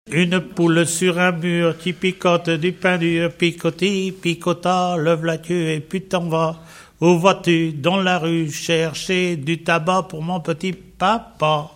L'enfance - Enfantines - rondes et jeux
Pièce musicale inédite